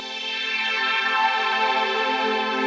SaS_MovingPad03_90-A.wav